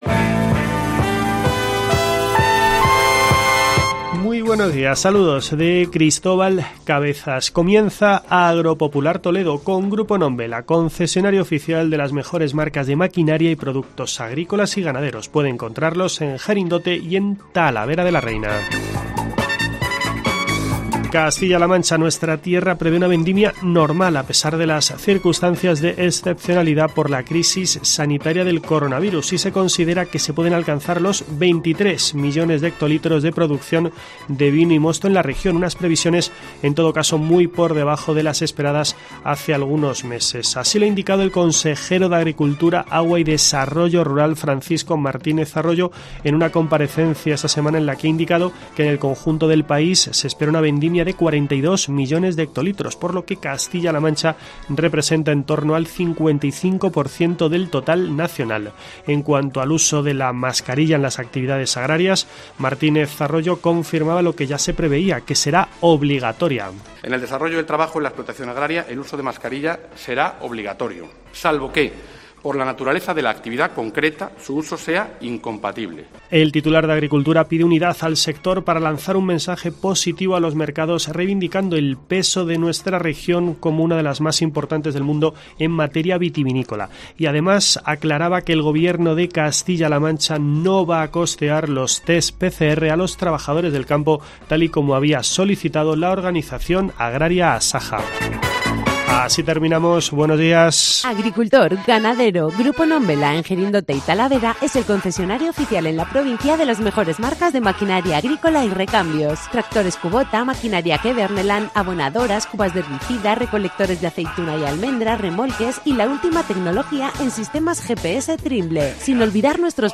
En rueda de prensa, el consejero de Agricultura, Agua y Desarrollo Rural, Francisco Martínez Arroyo, acompañado por la directora general de Agricultura y Ganadería, Cruz Ponce, ha reivindicado el peso de Castilla-La Mancha en el sector vitivinícola, una comunidad autónoma que con cerca de 500.000 hectáreas, representa el 7 por ciento de la producción de vino a nivel mundial, siendo “la región más importante del mundo”.